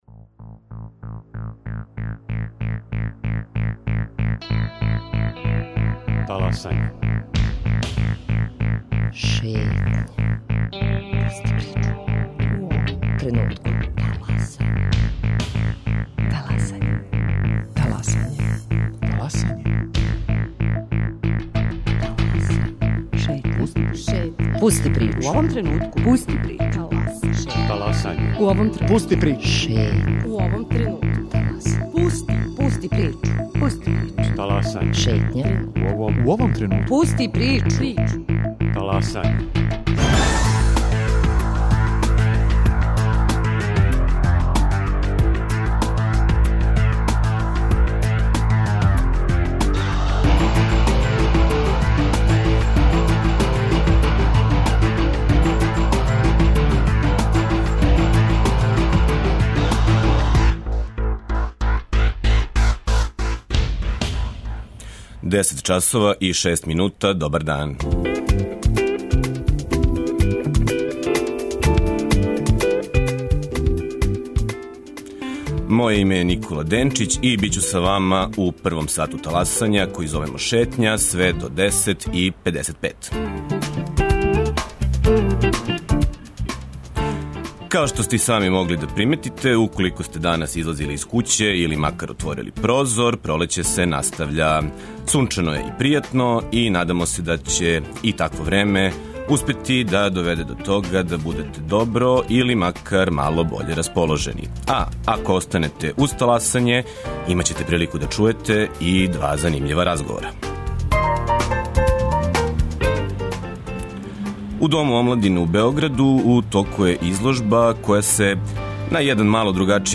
Шетњу настављамо разговором о плесу и представљамо трећи интернационални фестивал аргентинског танга Belgrade Tango Encuentro.